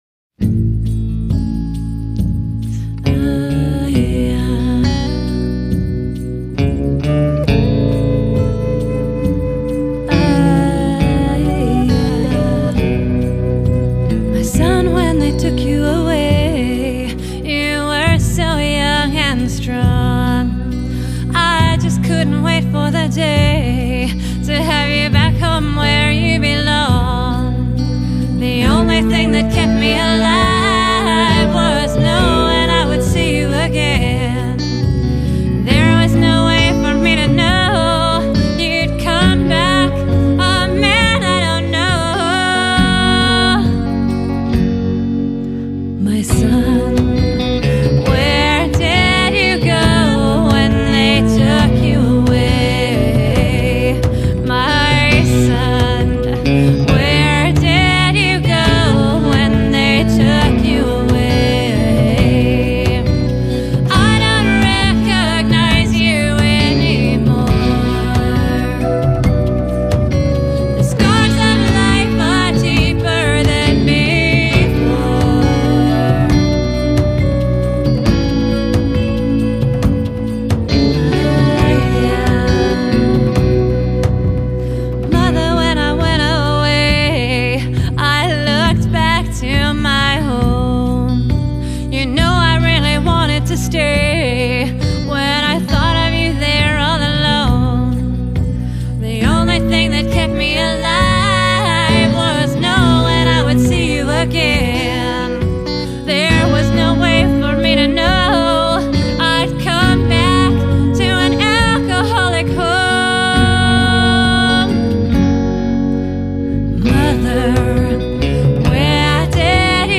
soul-stirring lament